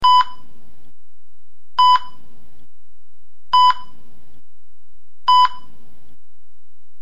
Звуки дефибриллятора
Возник краткий пульс